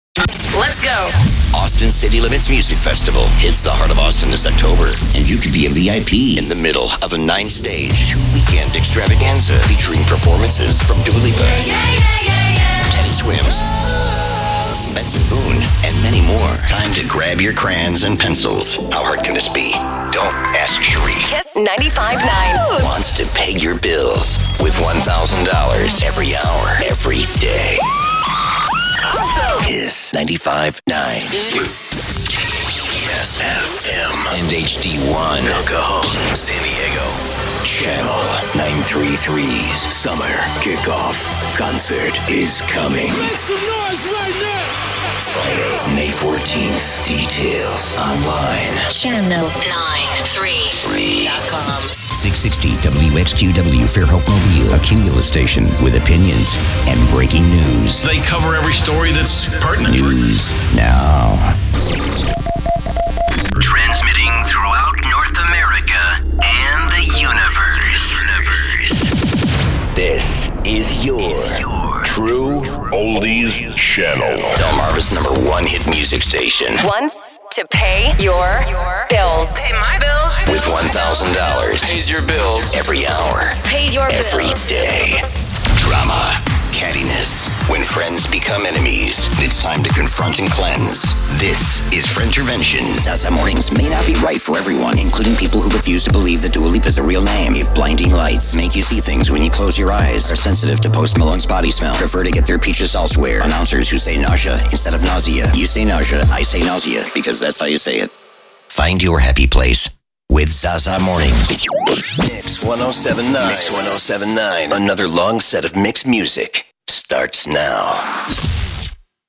Radio VO IMAGING